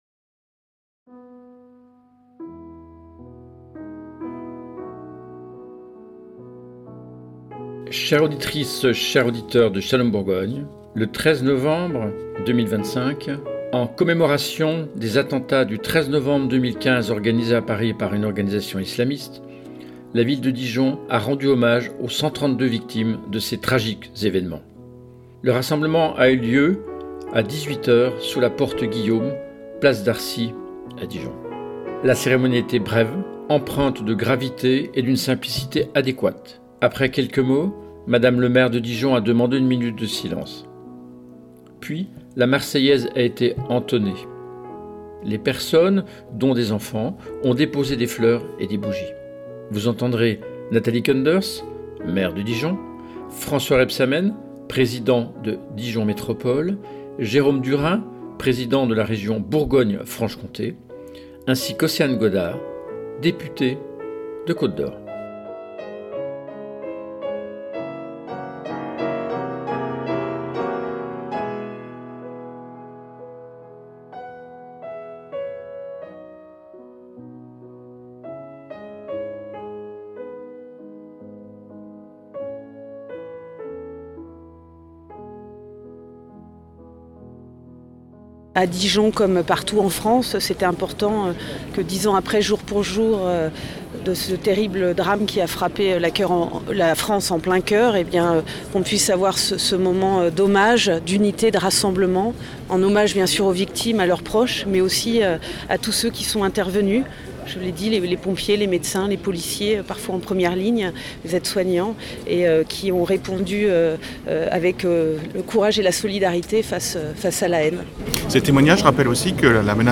Puis la Marseillaise a été entonnée.
Vous entendrez Nathalie Koenders, maire de Dijon, François Rebsamen président de Dijon Métropole, Jérôme Durain, président de la Région Bourgogne Franche-Comté ainsi qu’Océane Godard députée de Côte d’Or.